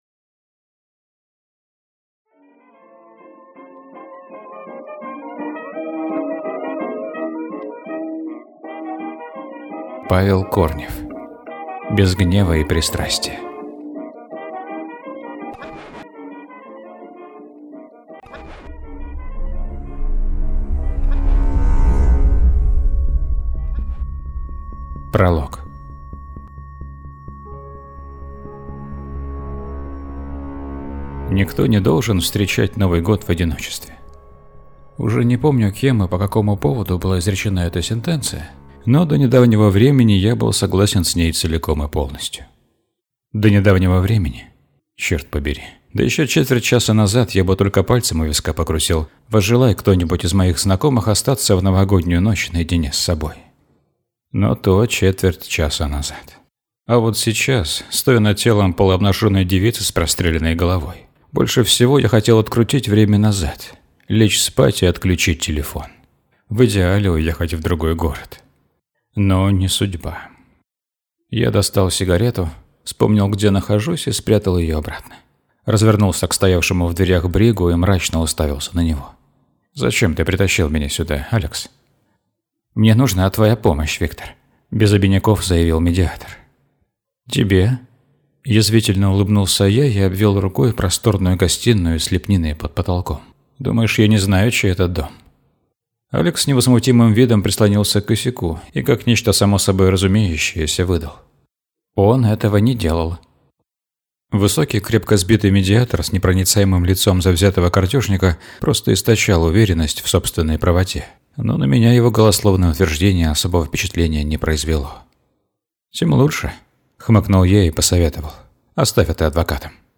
Аудиокнига Без гнева и пристрастия | Библиотека аудиокниг